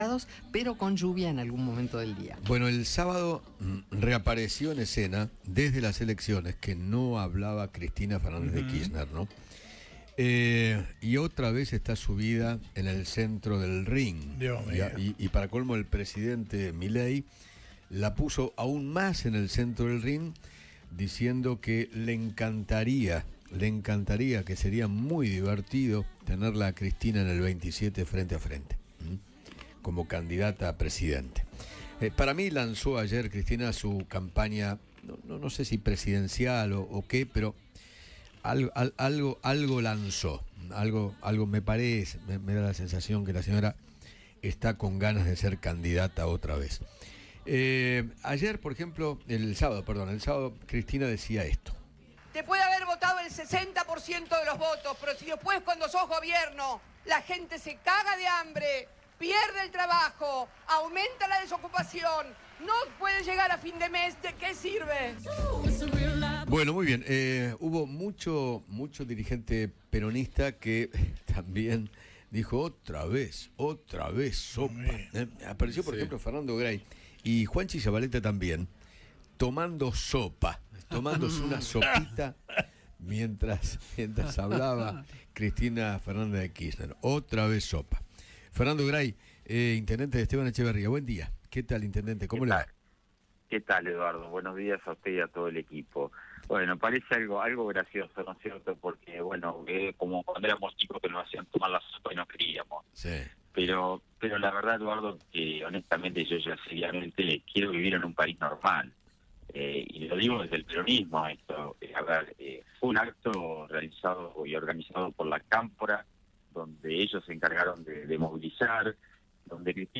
Fernando Gray, intendente de Esteban Echeverría, habló con Eduardo Feinmann sobre el discuros que dio Cristina Kirchner en Quilmes.